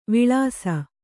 ♪ viḷāsa